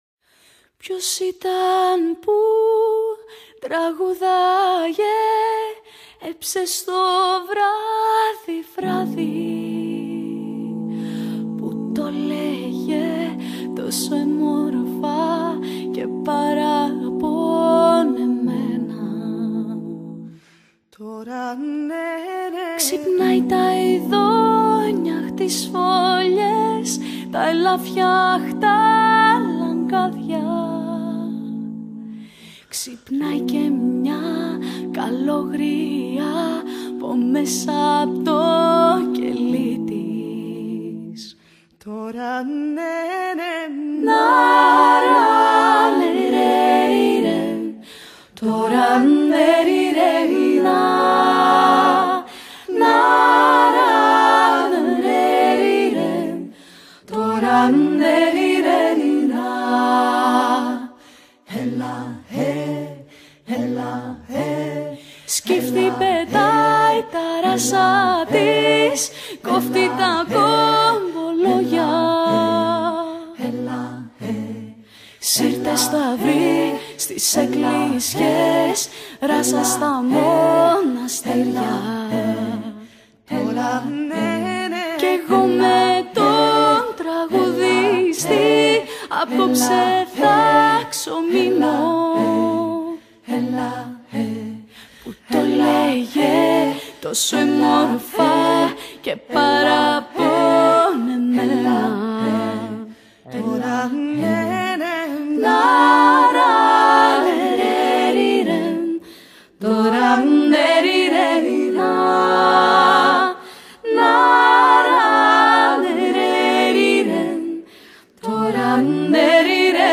vocal group